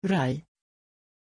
Aussprache von Rei
pronunciation-rei-sv.mp3